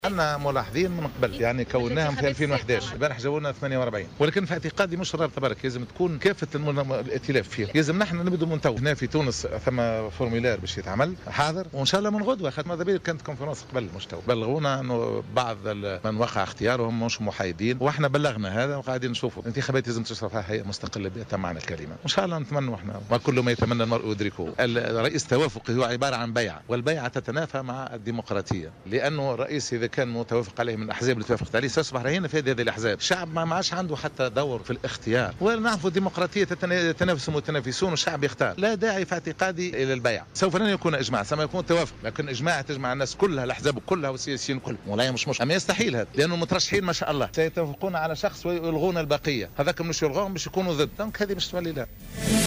أكد رئيس الرابطة التونسية لحقوق الإنسان،عبد الستار بن موسى في تصريح ل"جوهرة أف أم" على هامش ندوة صحفية انعقدت اليوم للإعلان عن مكونات التحالف المدني من أجل الانتخابات رفض الرابطة لمبادرة حركة النهضة والبحث عن رئيس توافقي.